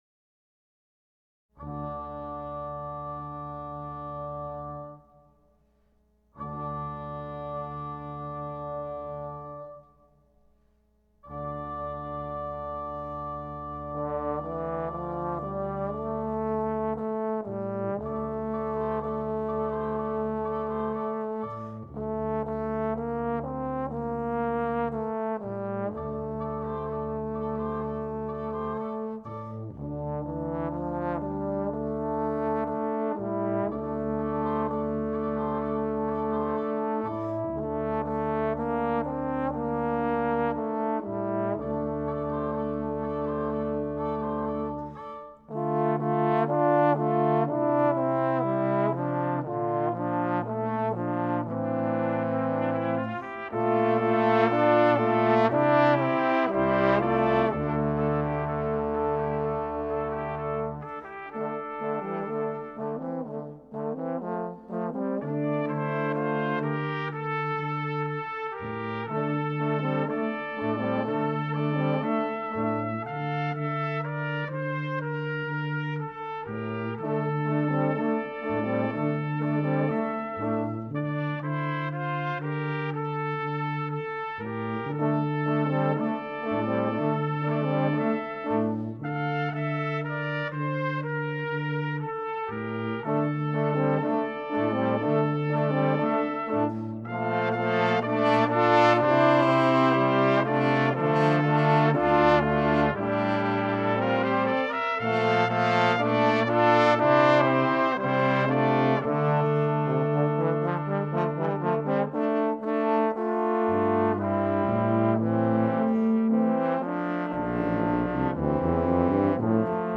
brass quintet
Christmas hymn